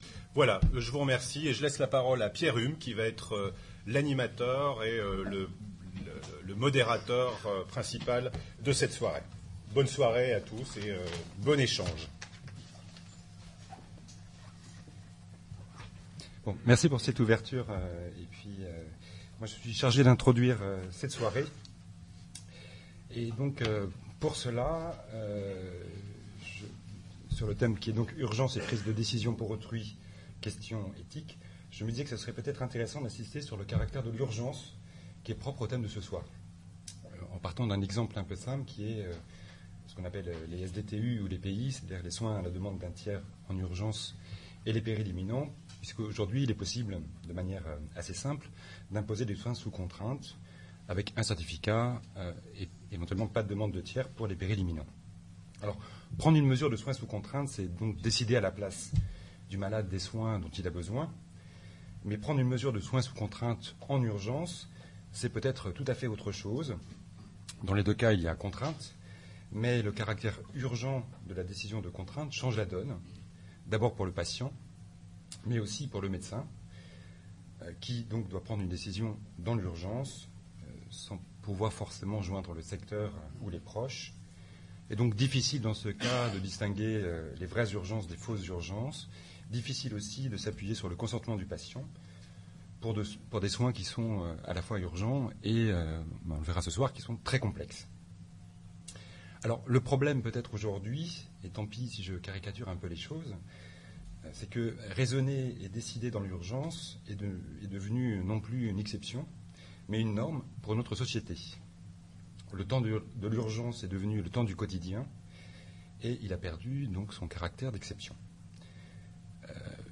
• Propos introductifs